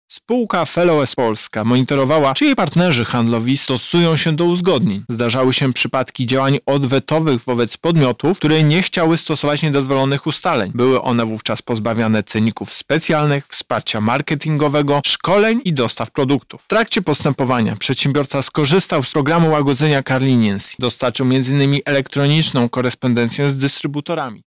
– W efekcie konsumenci, firmy oraz instytucje przez wiele lat nie mieli możliwości ich zakupu po niższych cenach.- mówi Prezes UOKiK Tomasz Chróstny.